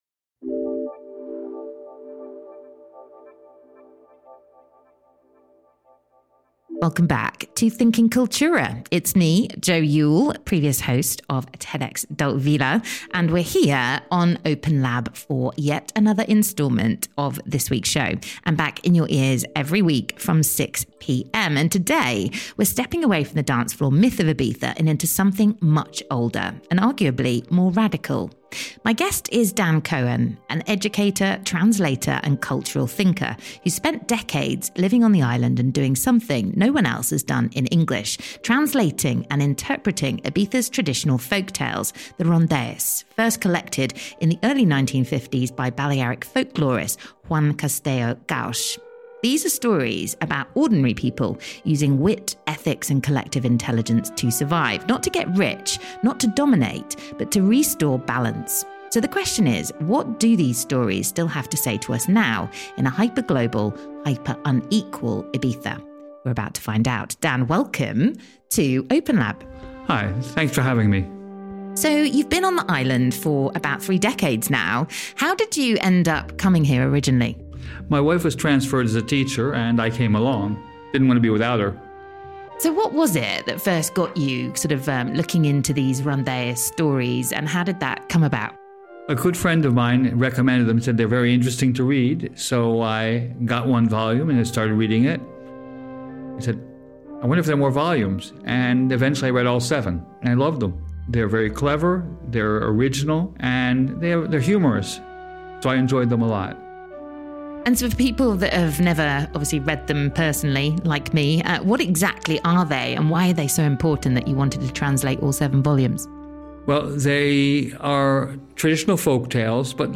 In this chat we explore: How language shapes identity The art (and responsibility) of translation What Ibiza looks like through a translator’s lens Why words matter more than ever in a divided world The hidden stories behind bringing books to life in another language